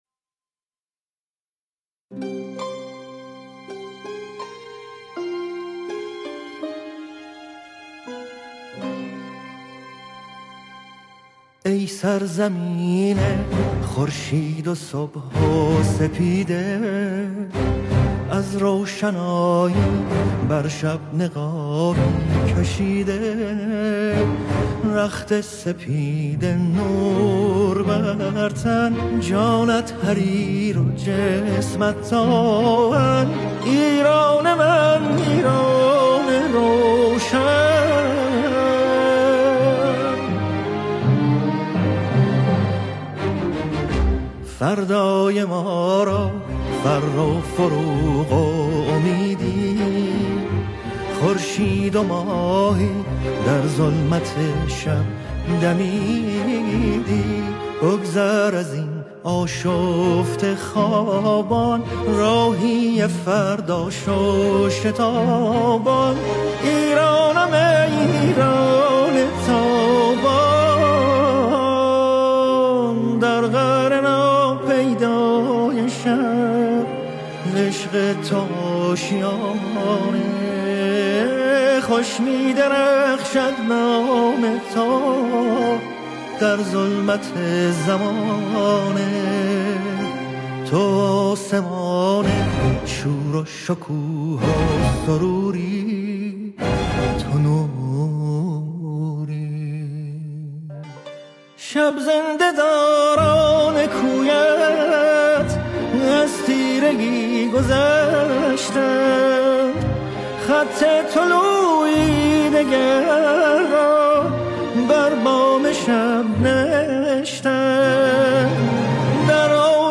تک آهنگ ملی میهنی